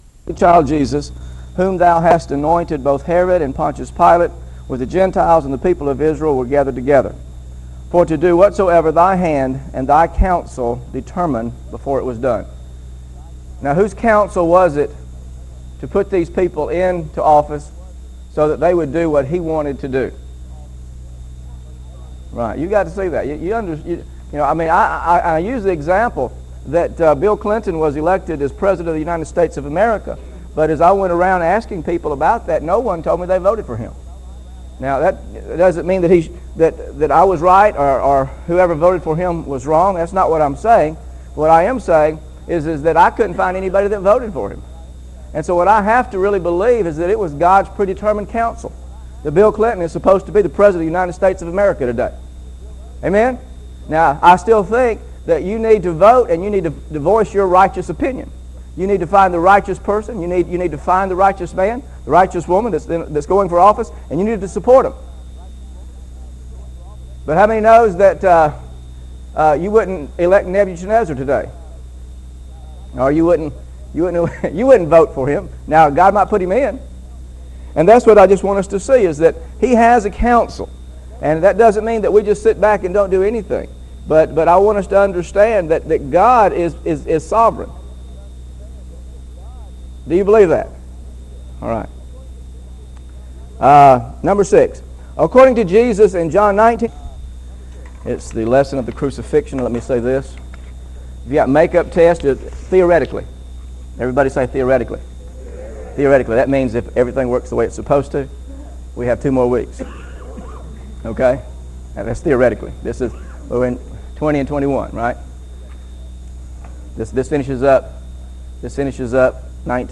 Scriptures used in this lesson: John Chapter 19 John Chapter 19 John Chapter 19 Also see